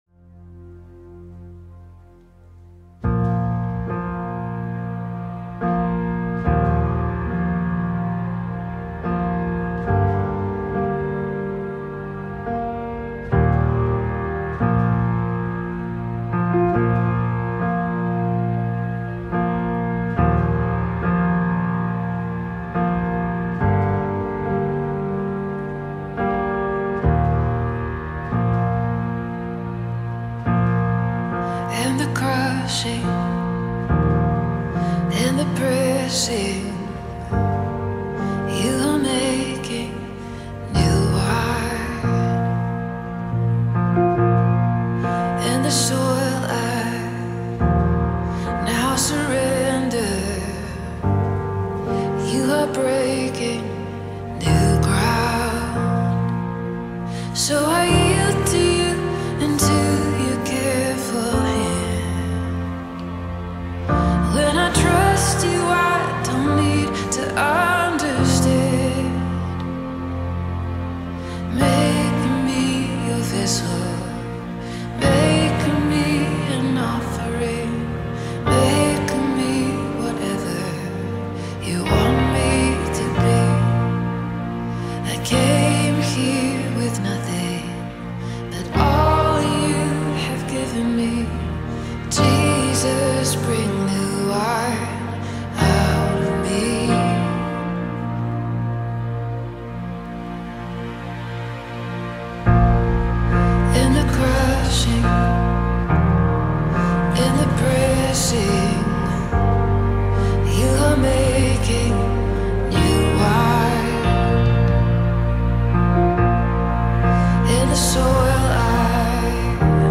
Live music Mp3 and Lyrics are available.